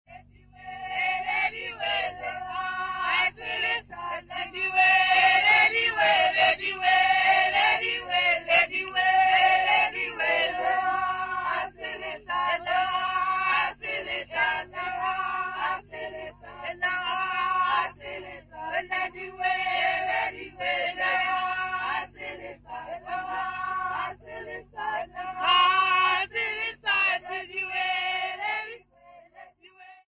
Folk music Africa
Sacred music South Africa
Field recordings South Africa
Africa South Africa Ficksburg, Free State Province sa
Unaccompanied traditional Sesotho song